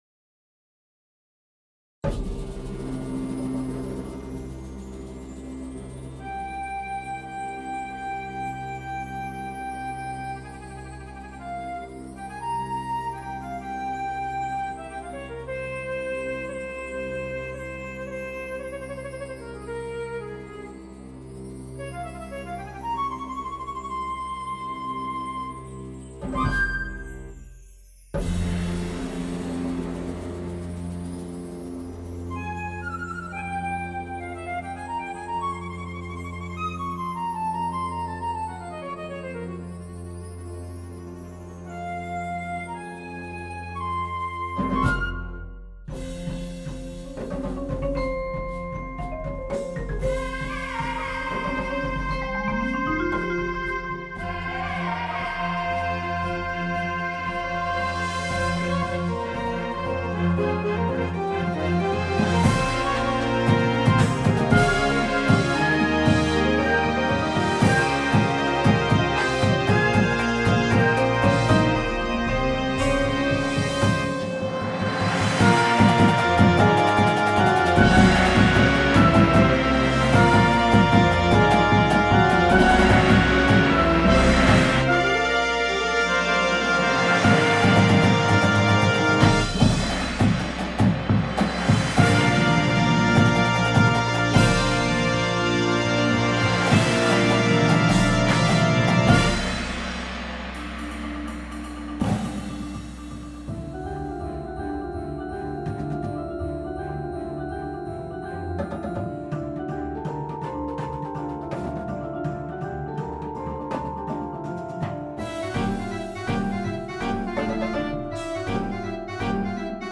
along with sound design
• Flute
• Clarinet 1, 2
• Alto Sax 1, 2
• Trumpet 1
• Horn in F
• Trombone 1, 2
• Tuba
• Snare Drum (With optional mounted Dumbek)
• Tenors – 5 Drums (4 drum parts available upon request)
• Synthesizer – Two parts
• Marimba – Two parts (With hand drum)
• Vibraphone – Two parts (With hand drum)
• Glockenspiel (With Shekere and Crash Cymbals)